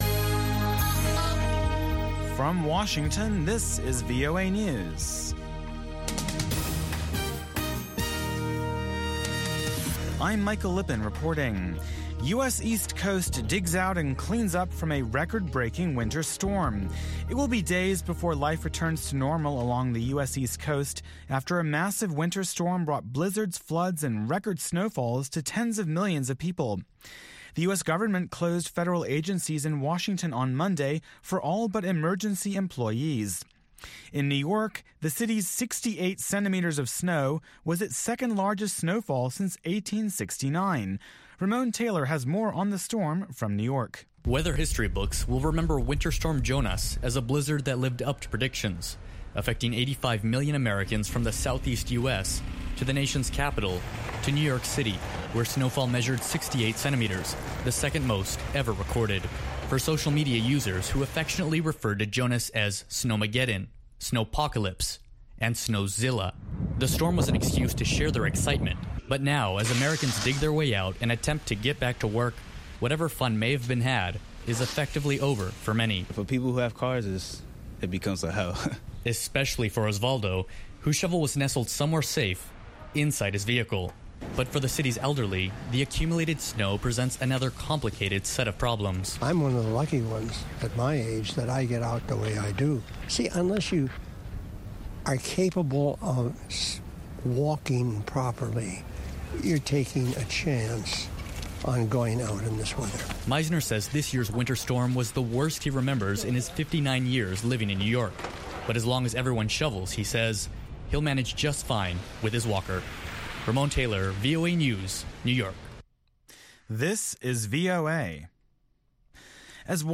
from N’dombolo to Benga to African Hip Hop
the best mix of pan-African music